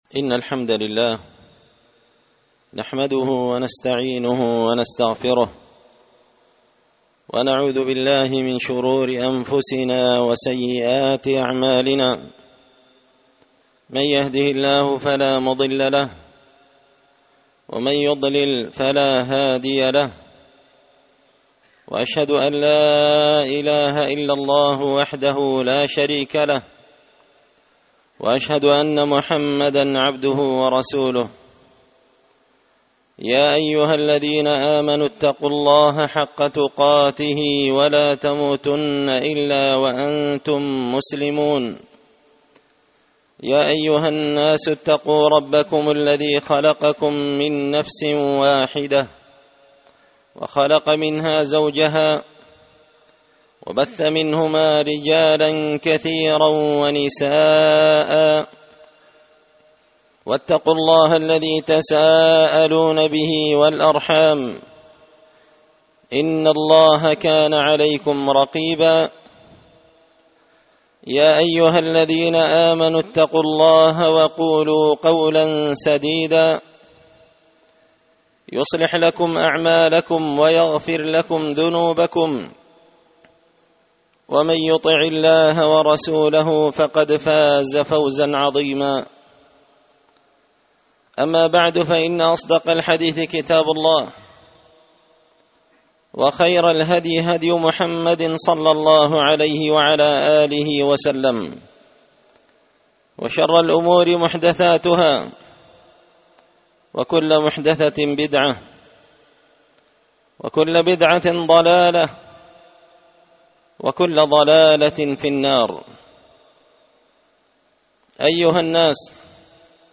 خطبة جمعة بعنوان -24رجب 1443هـ
دار الحديث بمسجد الفرقان ـ قشن ـ المهرة ـ اليمن